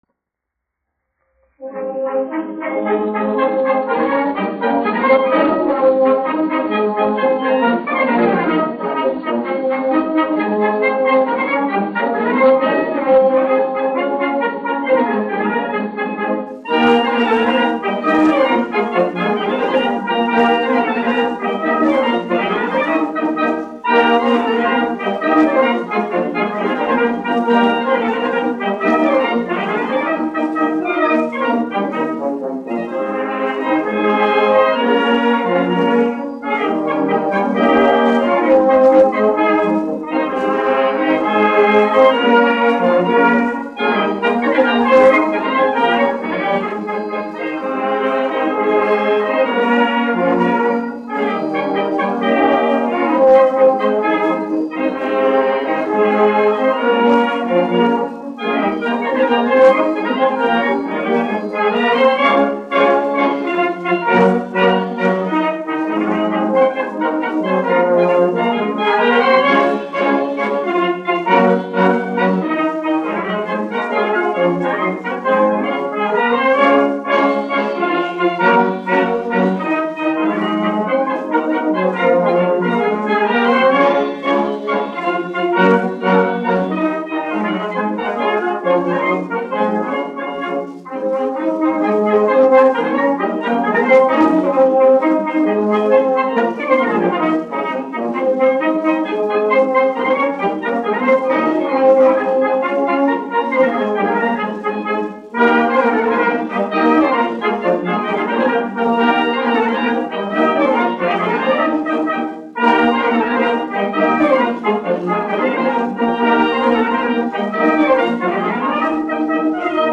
1 skpl. : analogs, 78 apgr/min, mono ; 25 cm
Polkas
Pūtēju orķestra mūzika
Skaņuplate
Latvijas vēsturiskie šellaka skaņuplašu ieraksti (Kolekcija)